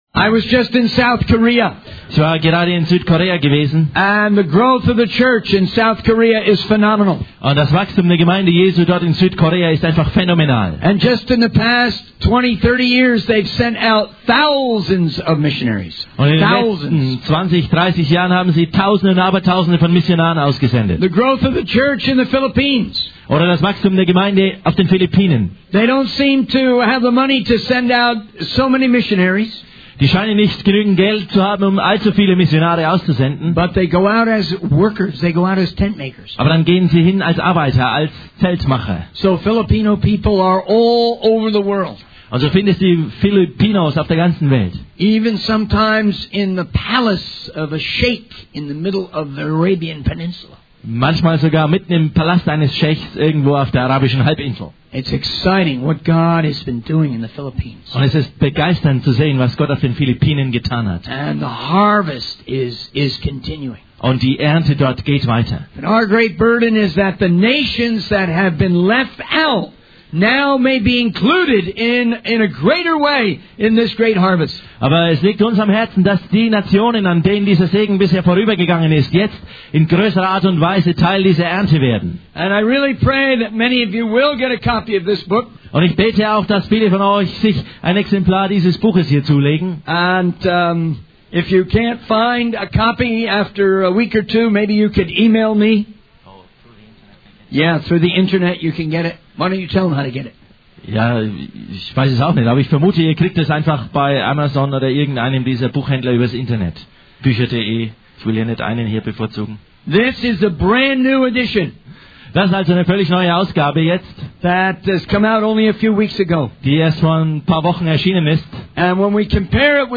In this sermon, the speaker begins by expressing gratitude for those who responded to his previous message with repentance and a deeper commitment to Jesus. He then transitions into a Bible study on Acts 13, focusing on the importance of being witnesses for Christ to the whole world. The speaker emphasizes the role of the Holy Spirit in empowering believers for this mission.